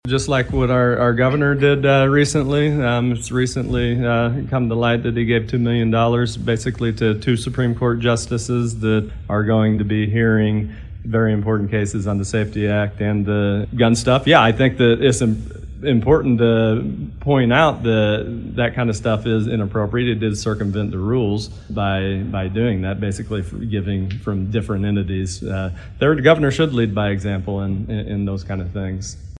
Also discussed during the news conference was the fact the Illinois Supreme Court set to hear arguments on the SAFE-T act next week.
Wilhour talks about that issue.